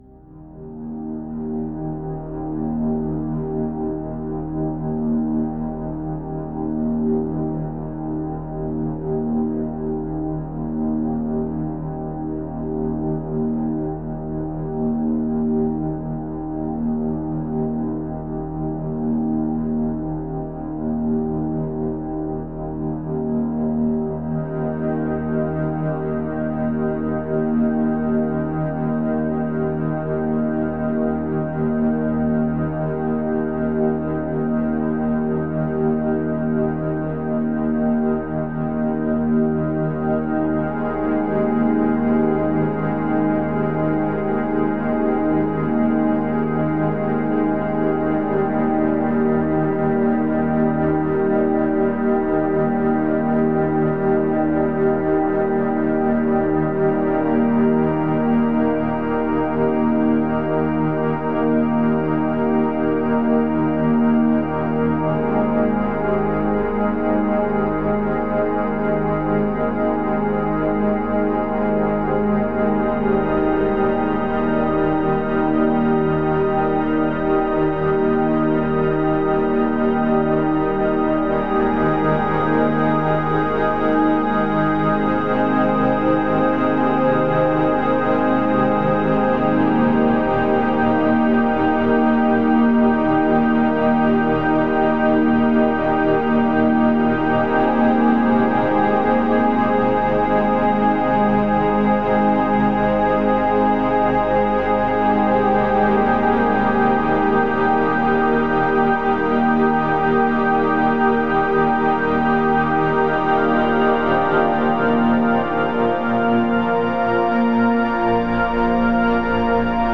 • Audio: Music